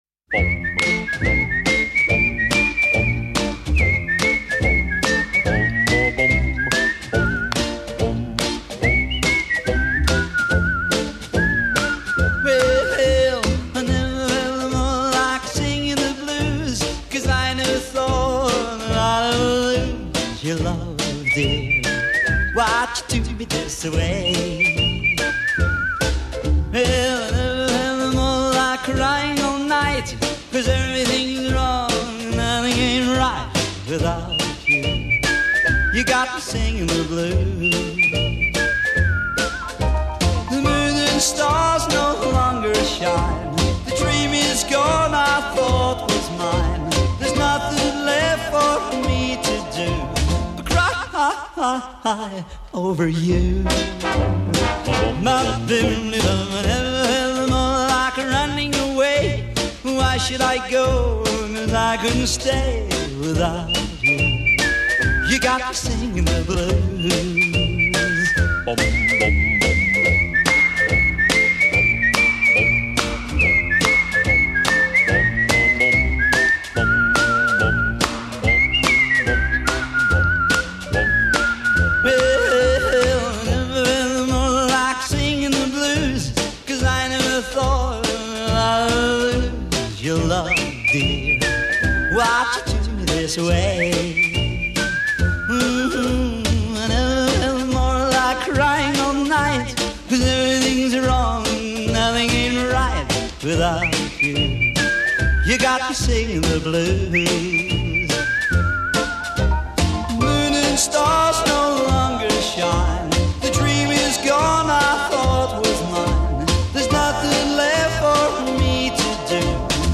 vocals and guitar
drums
sax
piano
A' intro 0:00 8 whistling with instrumental ensemble
A' break : 16 whistling solo over ensemble